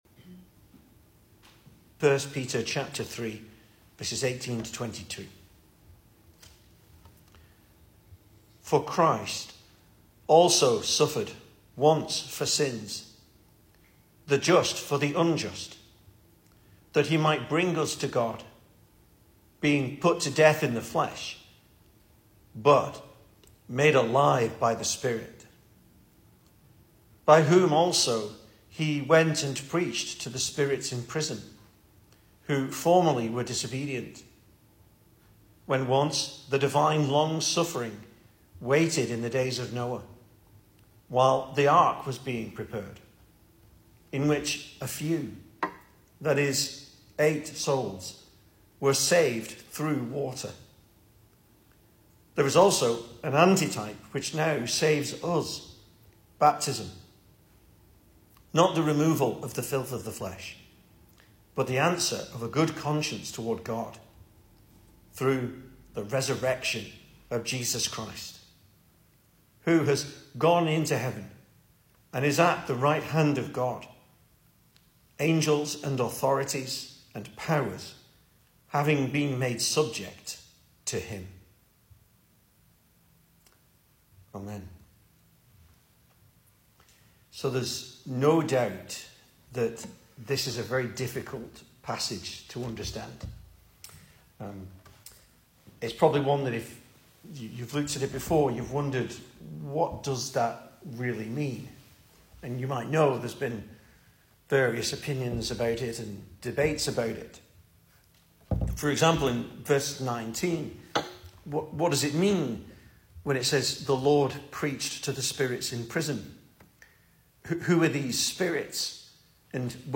2023 Service Type: Weekday Evening Speaker